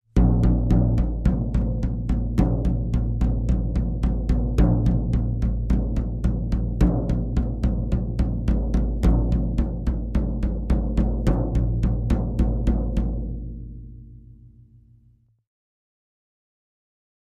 Drums|Tom | Sneak On The Lot